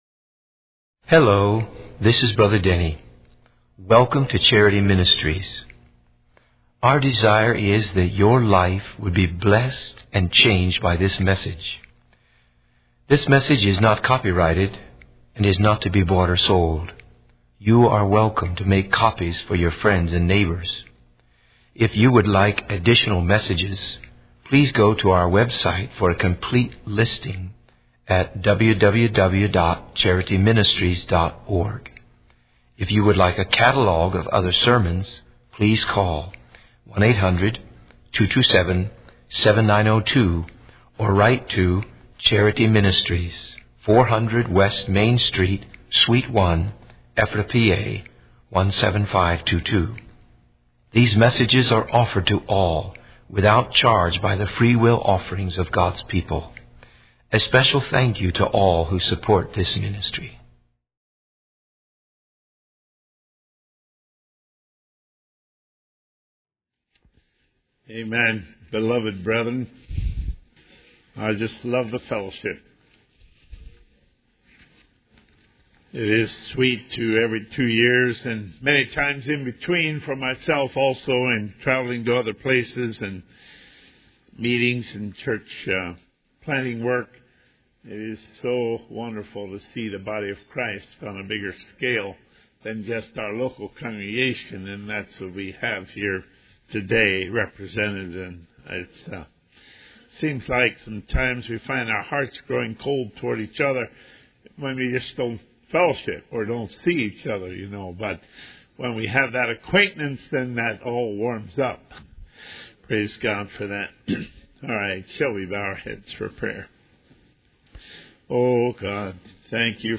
In this sermon, the preacher emphasizes the importance of following the teachings of Jesus as outlined in the Sermon on the Mount.